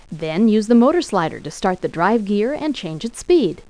1 channel
00309_Sound_invest.gears.mp3